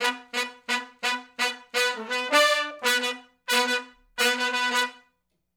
065 Funk Riff (B) uni.wav